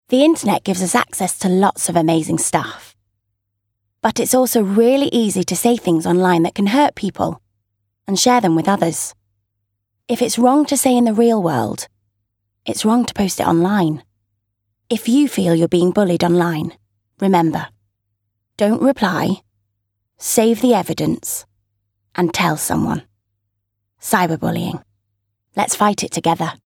• Female
Straight, informative.